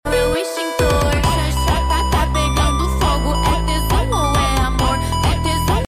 perdón por la mala Calidad 😭